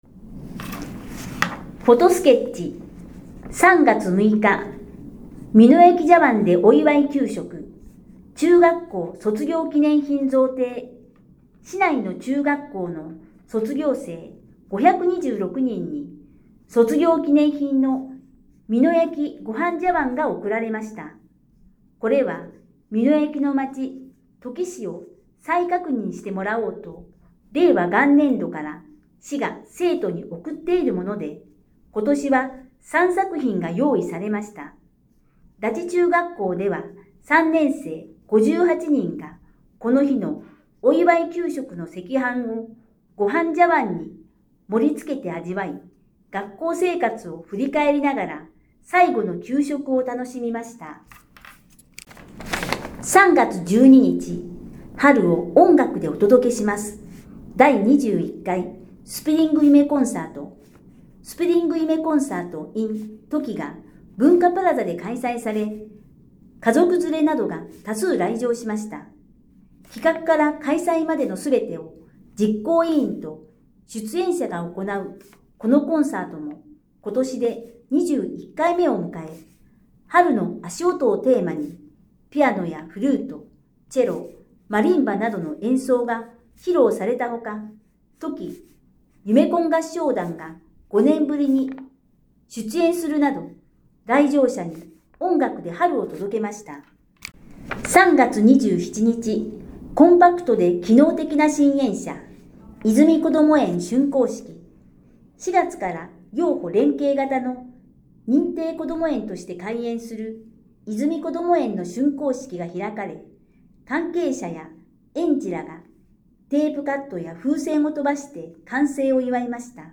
音声欄に表示があるものは、「声の広報」として音声で聞くことができます。